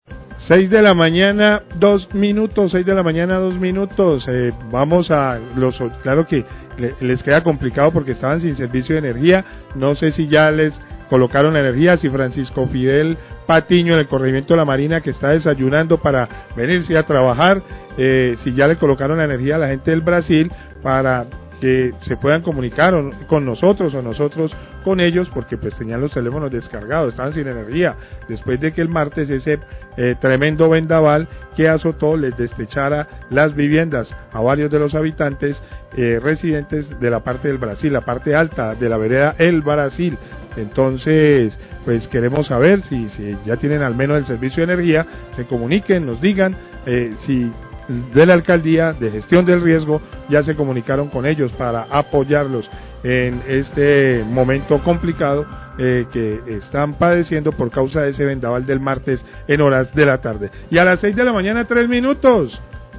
Periodista se pregunta si ya restablecieron la energía en vereda El Brasil de Tuluá
Radio